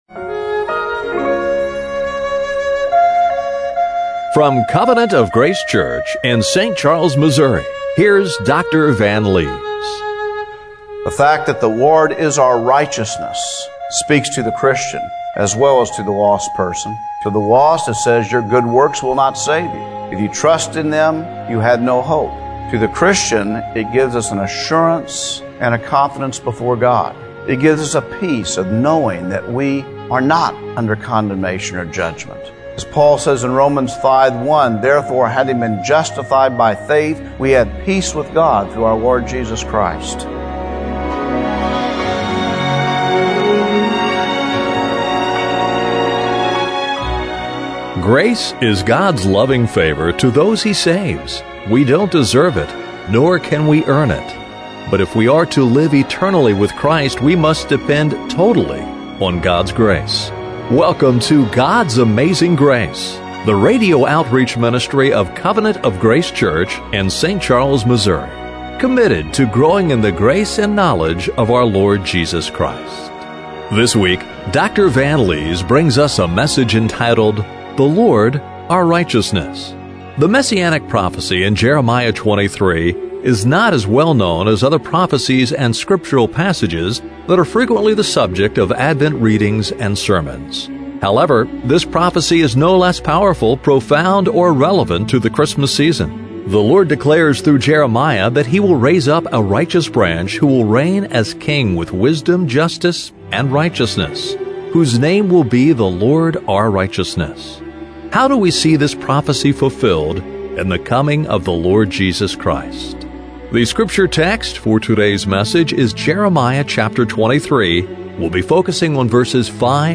Jeremiah 23:5-6 Service Type: Radio Broadcast How do we see this prophecy fulfilled in the coming of the Lord Jesus Christ?